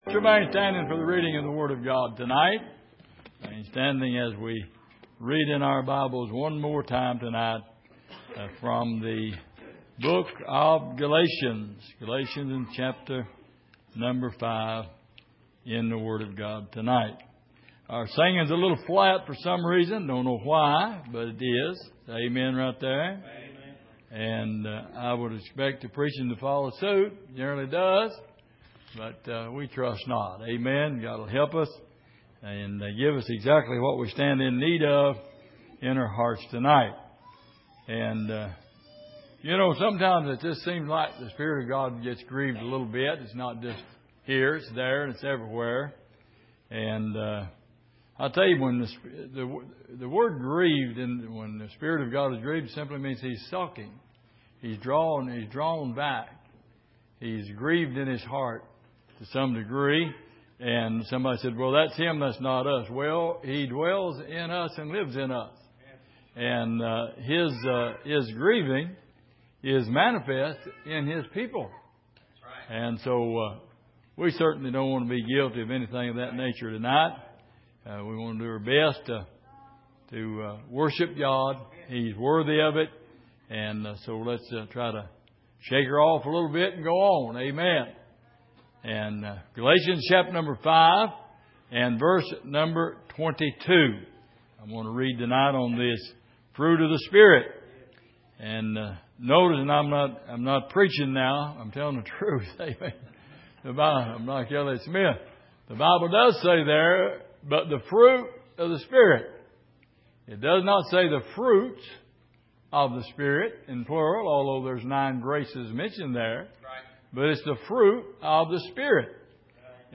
Miscellaneous Passage: Galatians 5:22-26 Service: Sunday Evening